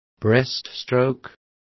Complete with pronunciation of the translation of breaststroke.